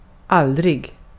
Uttal
IPA: /`aldrɪ(ɡ)/